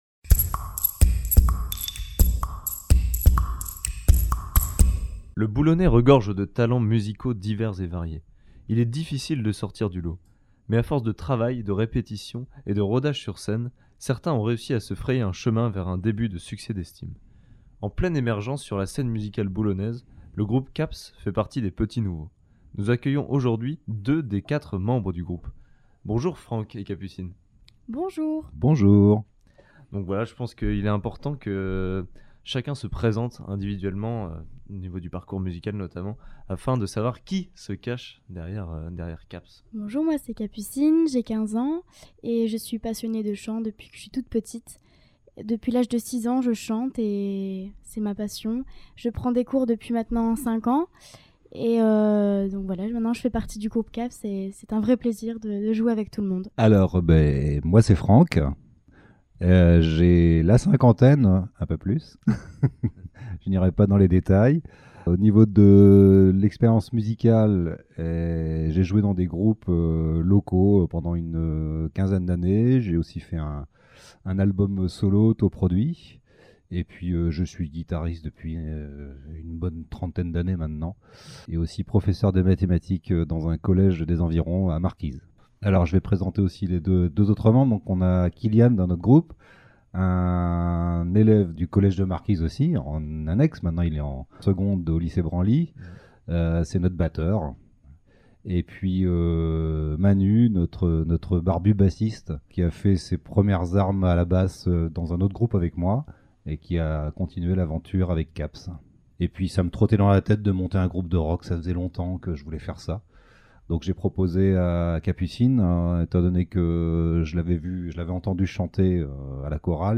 - Divers - ▼ Article de la Voix Du Nord ▼ ▼ Interview Transat FM ▼